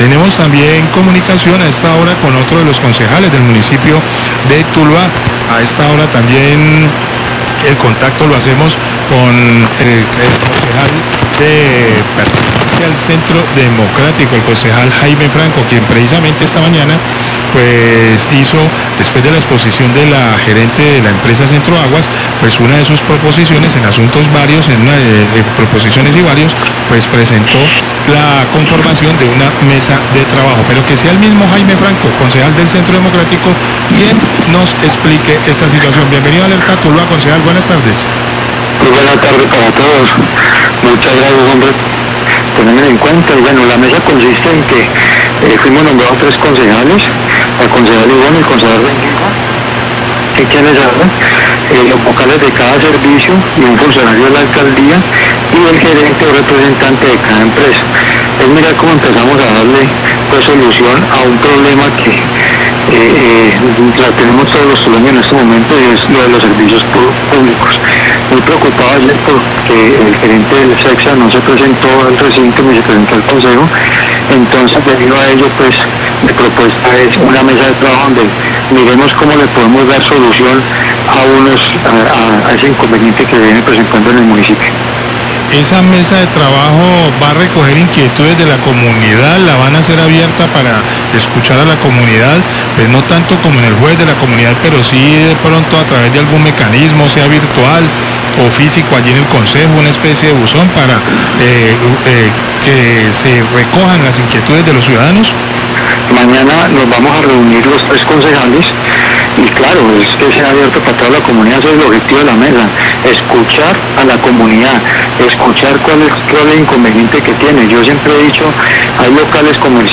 Radio
Habla el concejal Jaime Franco del Centro Democrático, propuso la conformación de una mesa de trabajo conformada por él, la concejal Toledo y el concejal Rengifo para reunirse con los vocales de control de servicios públicos para atender los reclamos de la comunidad por el incremento en el valor de los recibos, todo con el objetivo de buscar soluciones por parte de las empresas prestadoras.